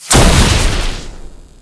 hit.ogg